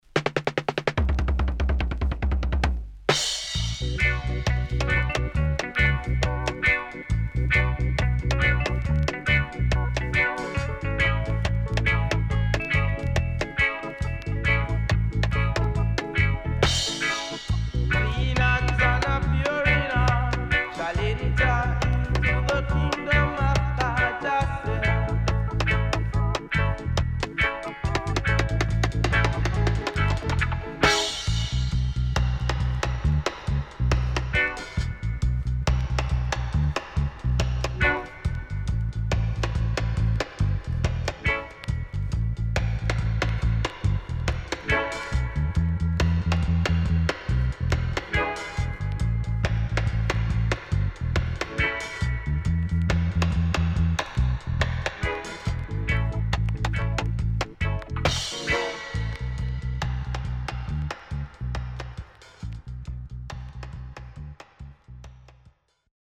Great Roots & Dubwise
SIDE A:少しチリノイズ入りますが良好です。